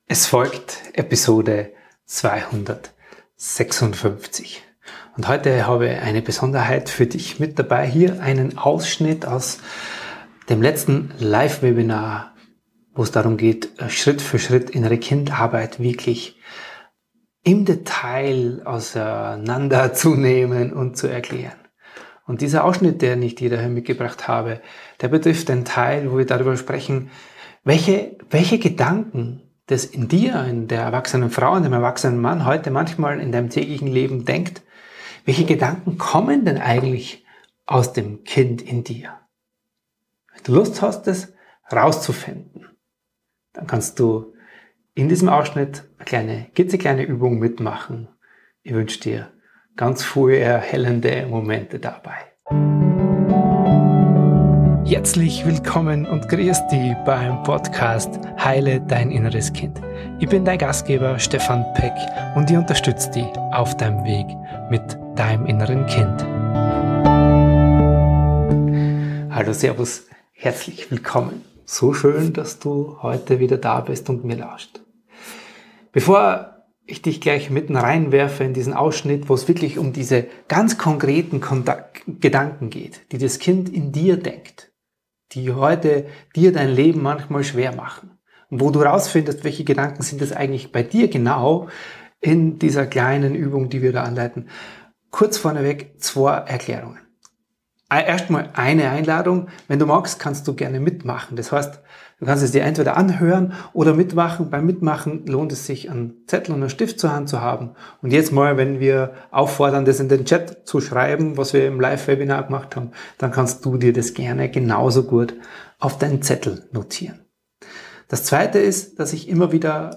Heute habe ich etwas ganz Besonderes für dich: einen Ausschnitt aus meinem letzten Live-Webinar. Darin geht es um die Gedanken, die dein inneres Kind bis heute in dir trägt – und wie du sie erkennen kannst.